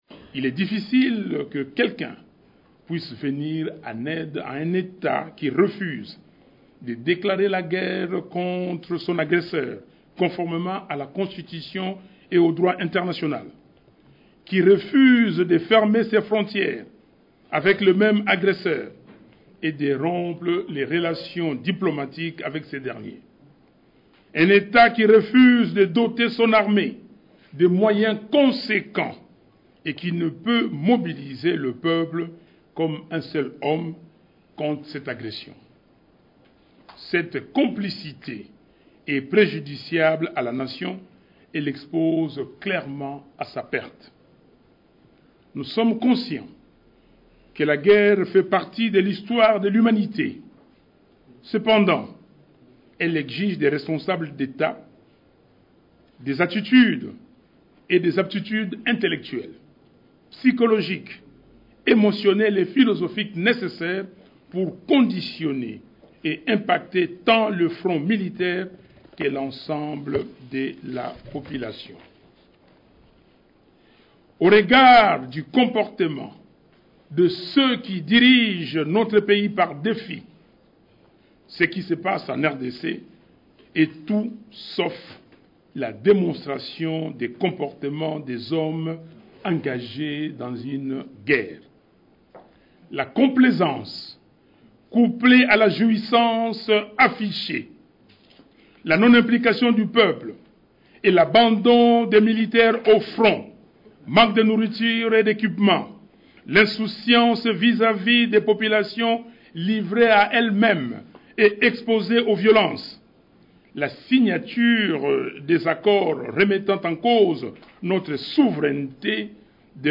Il s’exprimait ainsi, au cours d’une conférence de presse, au sujet de la guerre que mène la rébellion du M23, avec l’appui du Rwanda, dans l’Est de la RDC.